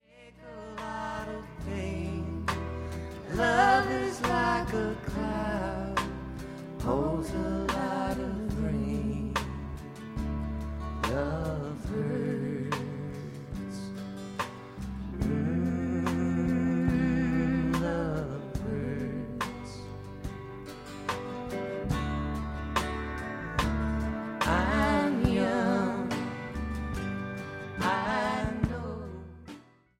1973 Live Version